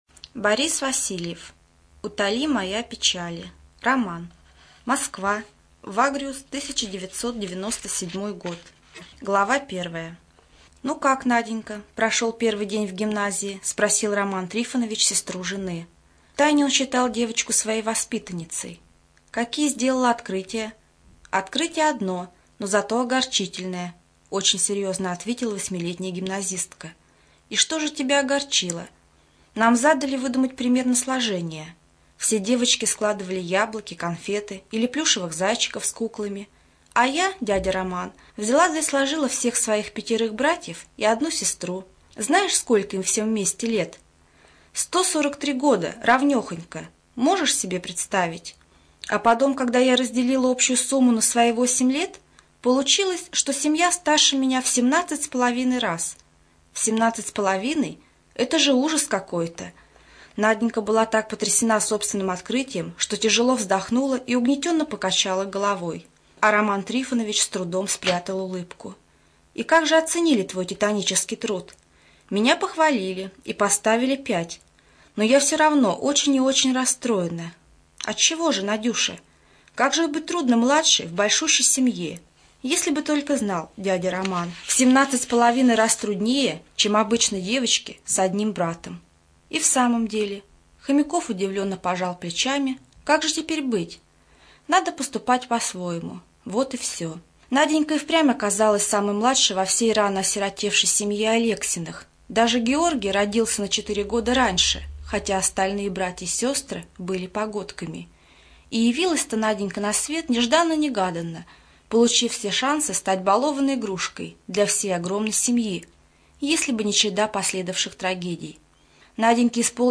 ЖанрИсторическая проза
Студия звукозаписиКемеровская областная специальная библиотека для незрячих и слабовидящих